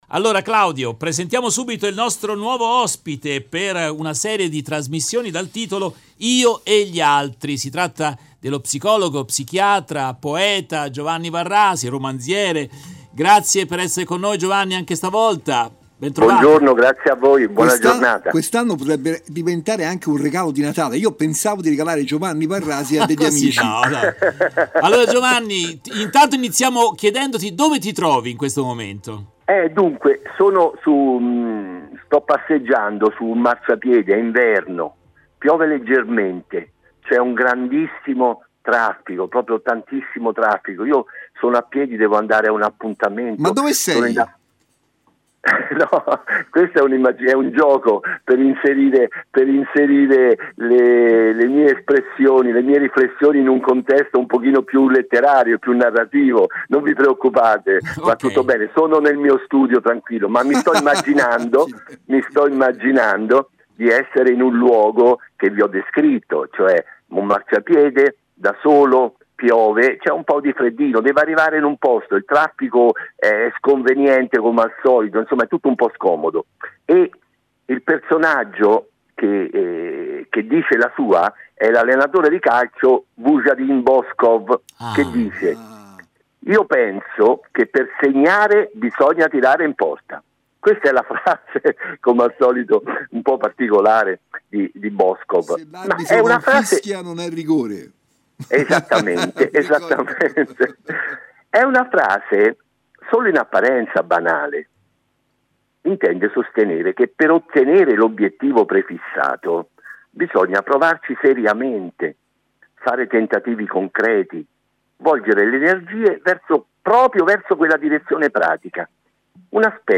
uno spazio radiofonico che propone pensieri
Nel corso della diretta RVS del 15 dicembre